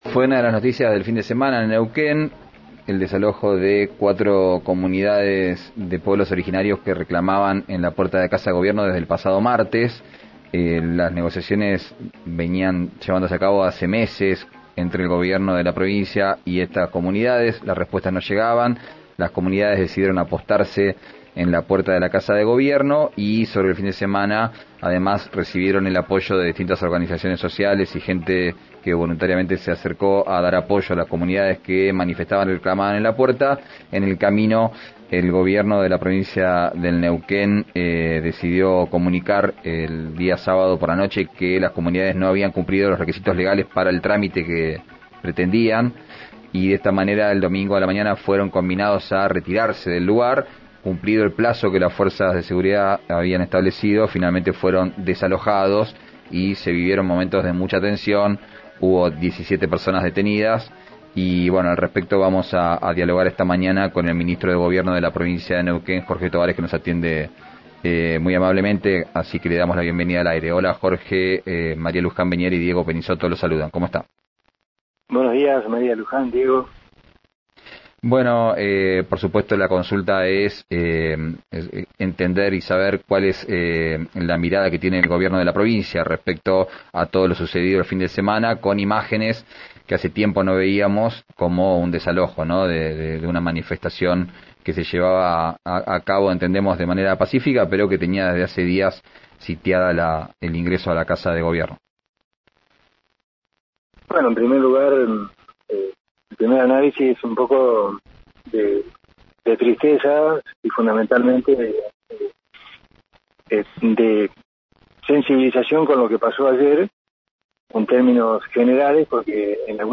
Escuchá al ministro de Gobierno de Neuquén, Jorge Tobares, en RÍO NEGRO RADIO: